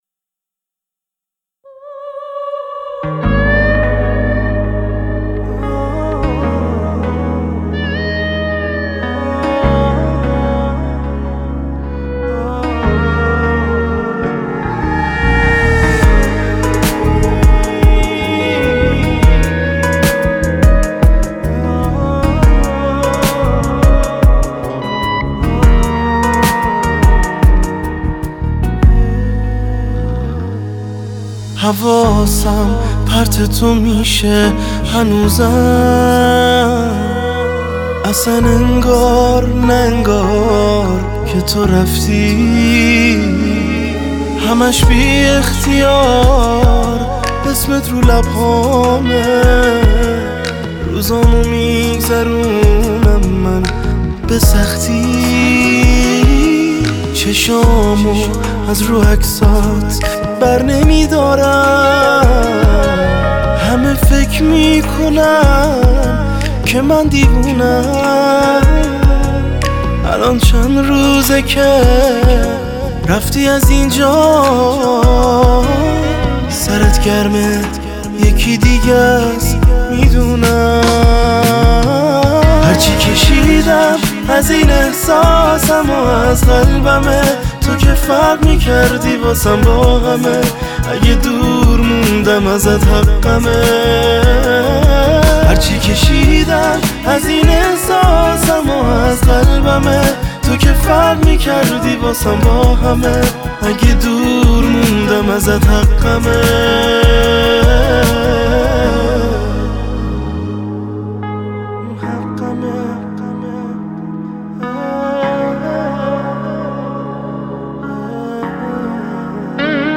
پاپ
آهنگ غمگین